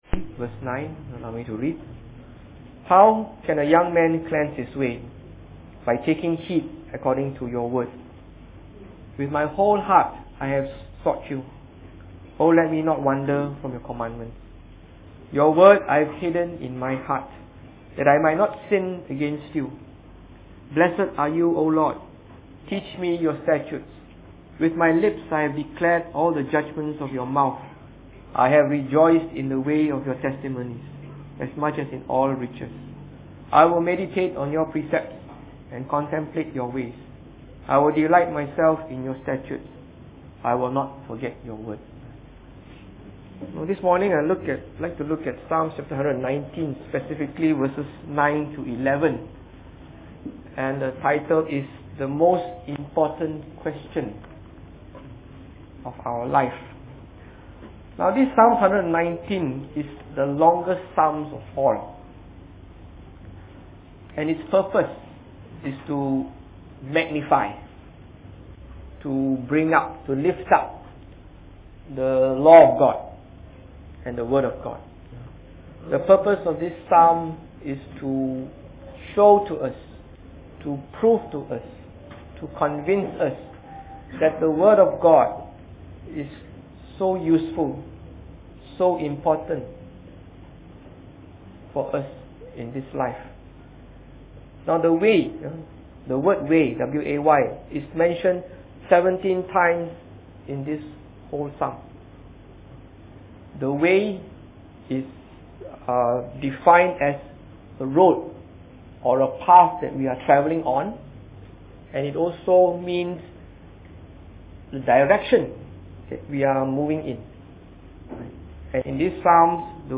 Preached on the 24th of February 2013.